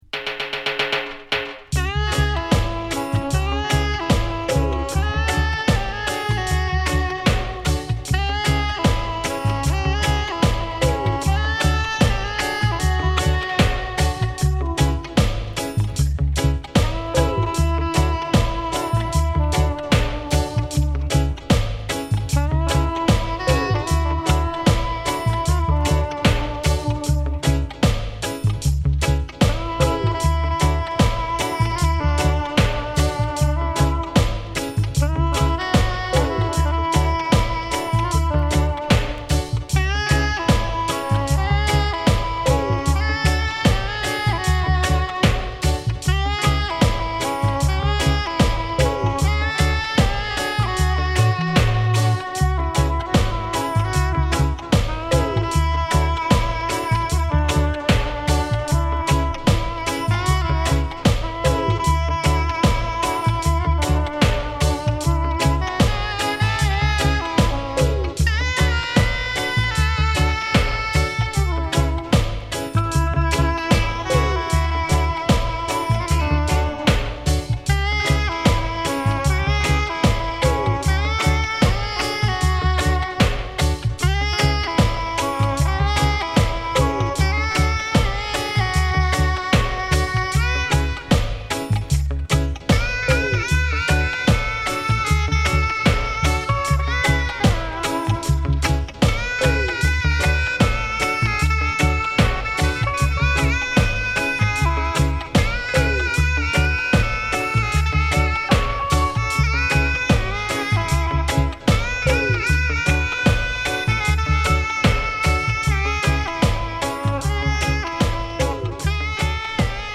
マシーンドラム独特のポップ感があり、なんともクリアな音。サックスとギターの掛けあいが抜群！
聴かせる哀愁メロディ満載の1枚。